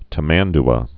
(tə-mănd-ə)